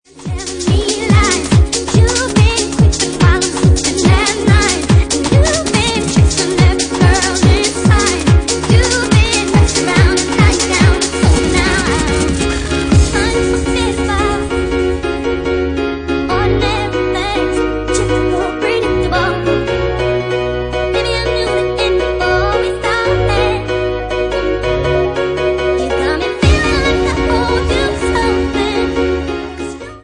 Genre:Bassline House
Bassline House at 143 bpm